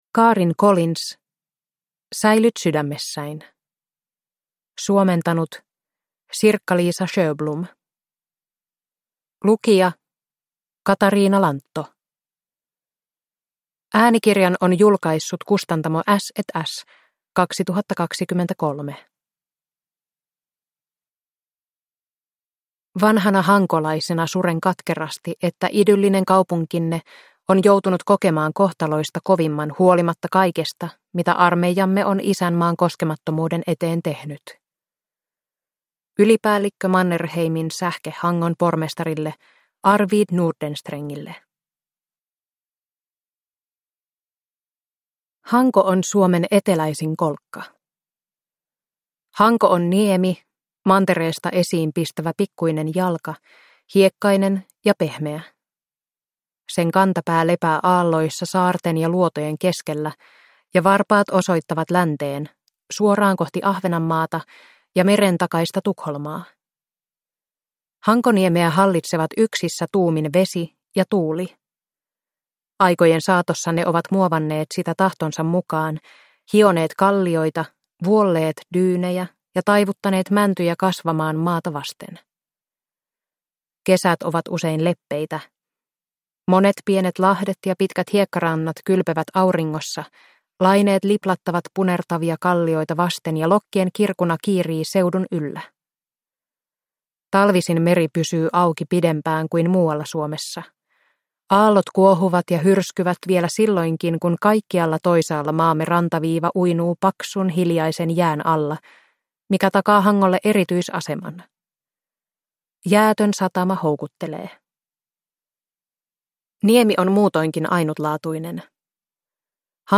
Säilyt sydämessäin – Ljudbok – Laddas ner